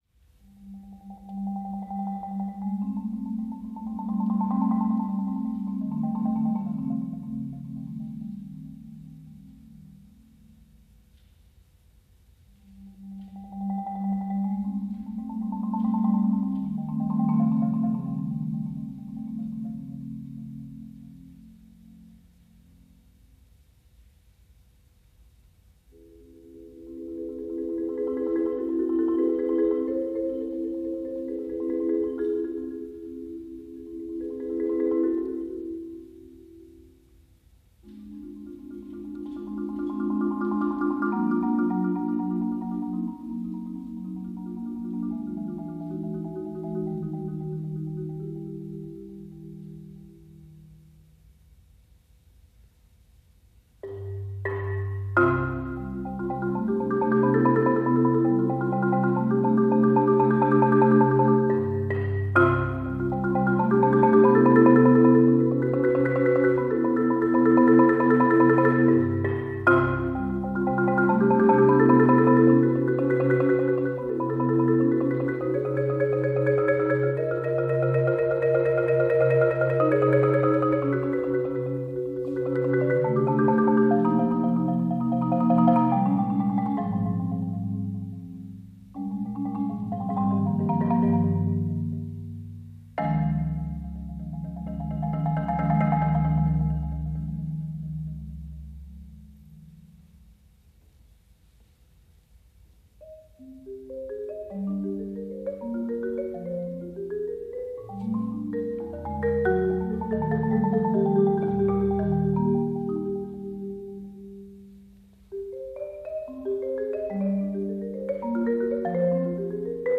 Voicing: Marimba